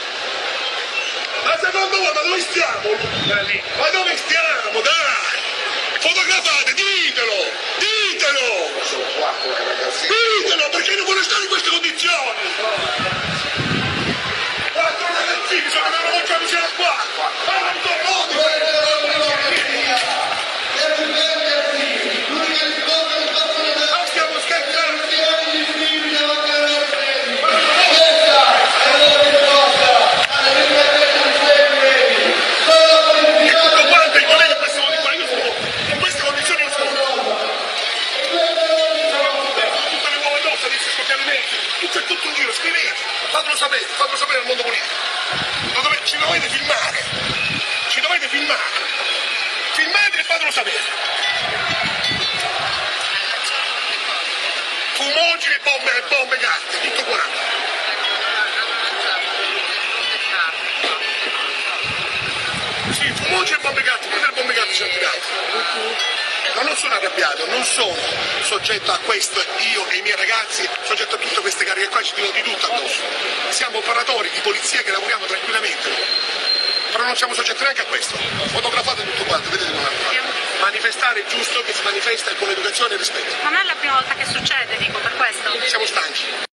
In folgendem Video ist der Chef der Polizeibrigade empört über die Anordnung der Regierung und weigert sich, unbewaffnete Menschen anzugreifen, mit Wasser zu übergießen und mit Tränengas zu besprühen. Er schreit und wirft seinen Helm auf den Panzerwagen: "Schiesse, zeig der ganzen Welt ... wie können wir etwas gegen unser Volk tun? Ich kann es nicht tun!"